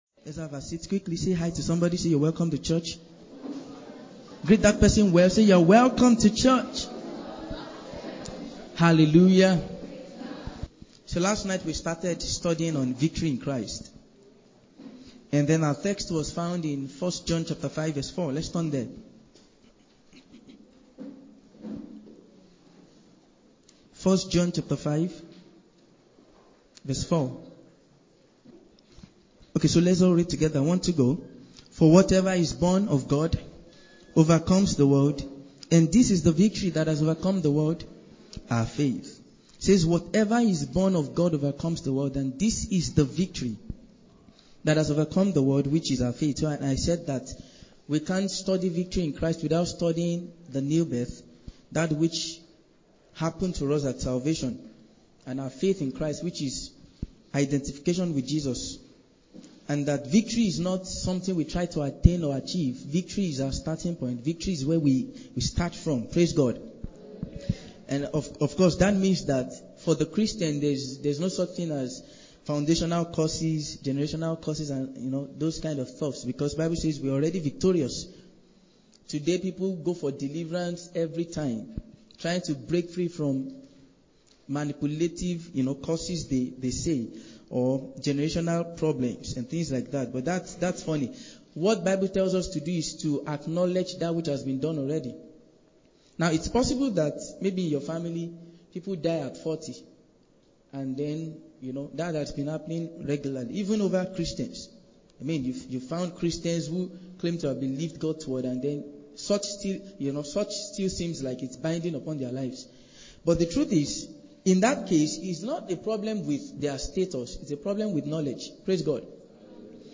Victory in Christ - Part 2 - TSK Church, Lagos